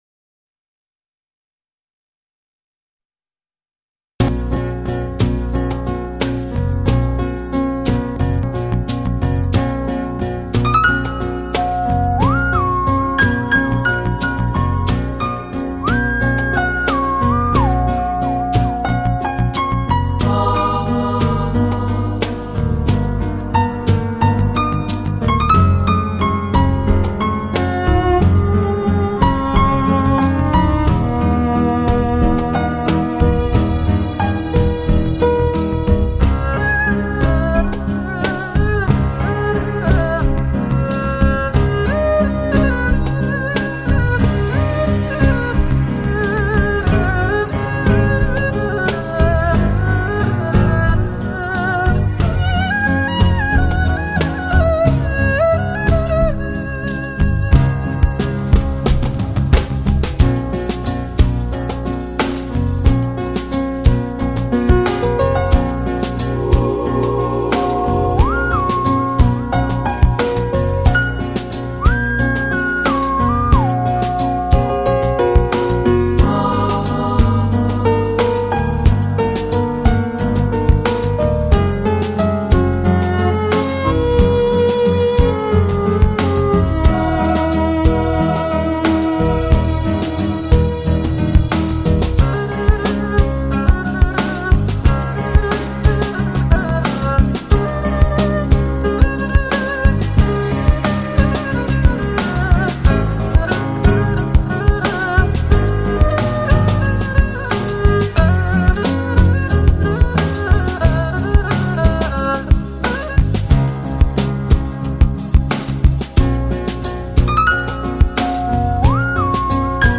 旋律优美，风格古朴，含蓄、儒雅